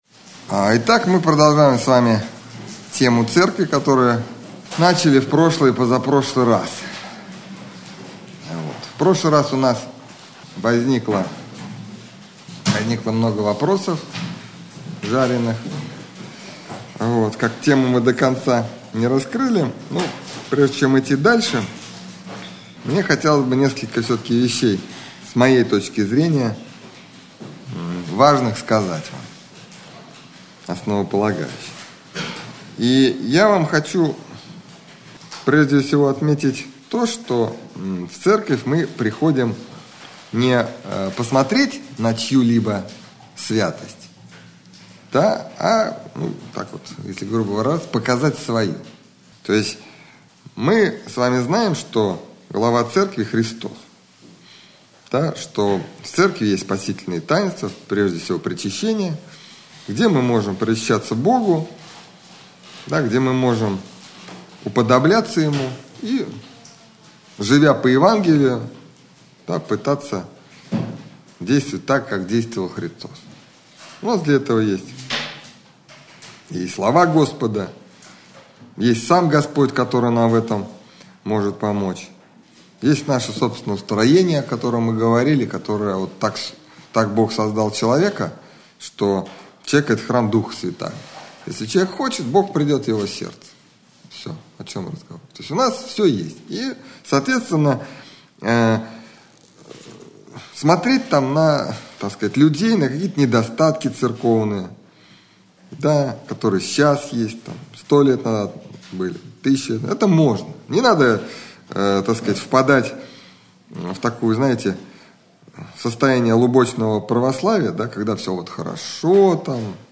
Общедоступный православный лекторий